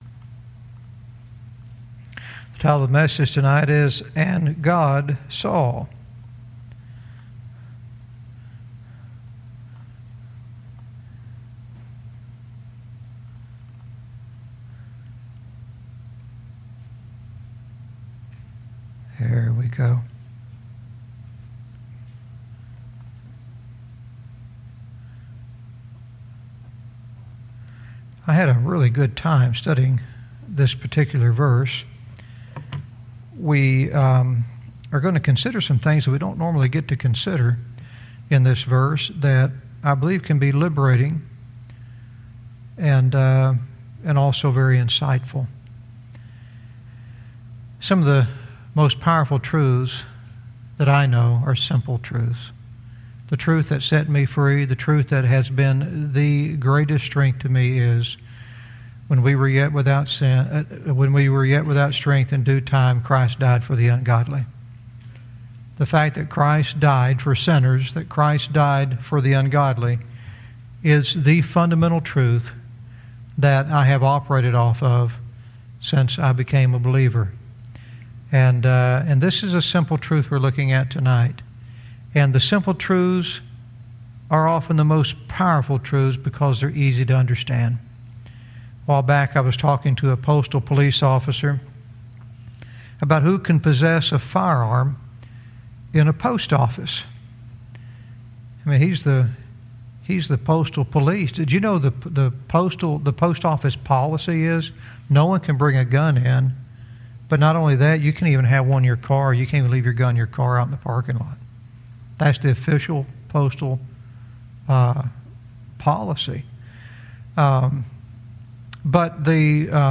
Verse by verse teaching - Proverbs 11:23 "And God Saw"